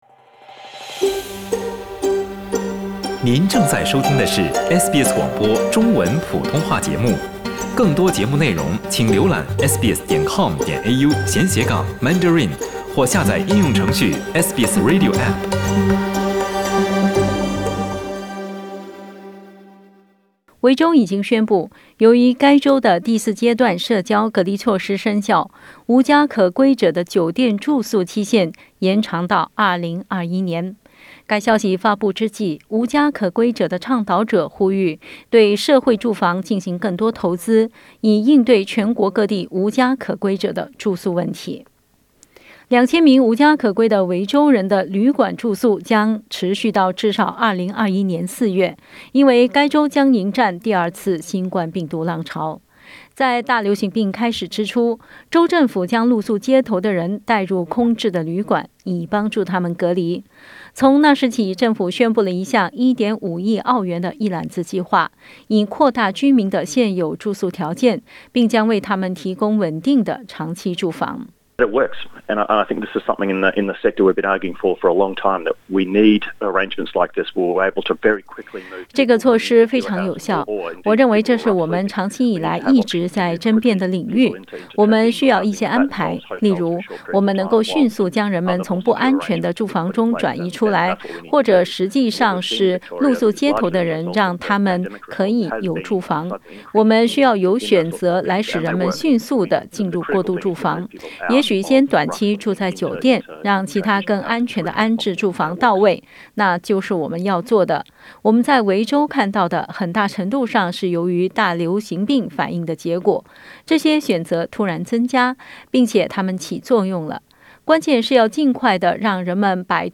點擊圖片收聽詳細報道。